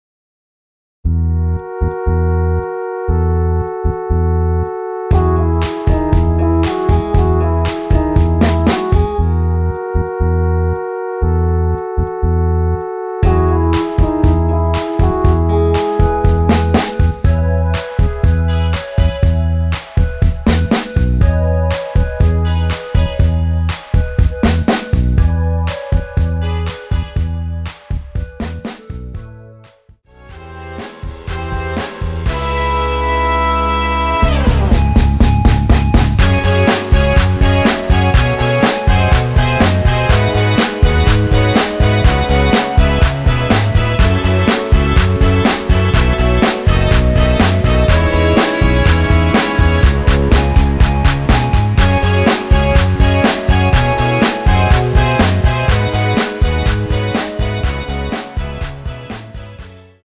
원키 멜로디 포함된 MR입니다.
멜로디 MR이라고 합니다.
앞부분30초, 뒷부분30초씩 편집해서 올려 드리고 있습니다.